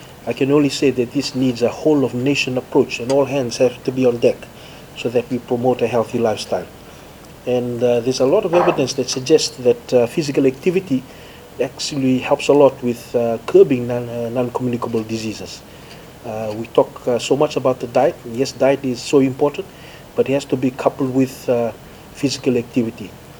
Launching the race, Health Minister Dr. Ifereimi Waqainabete says this is a great initiative to raise more awareness on the killer disease.